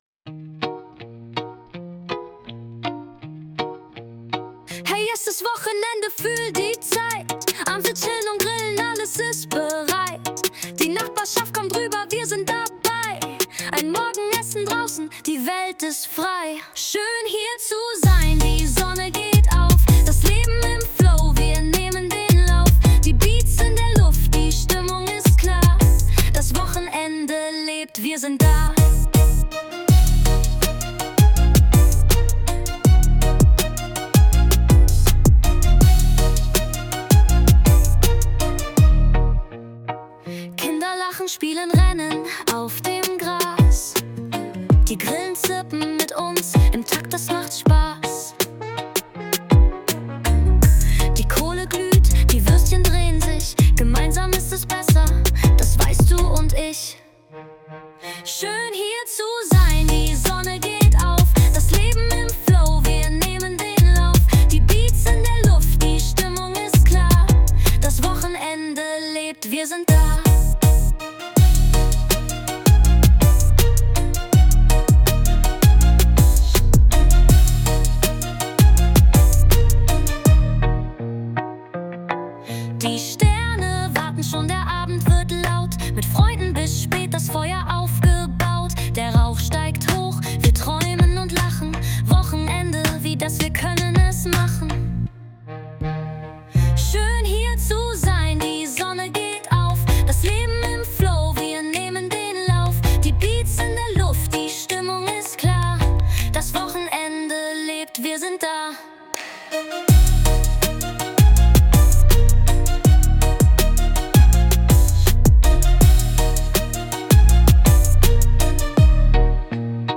Die Lieder wurden von einer K.I gemacht.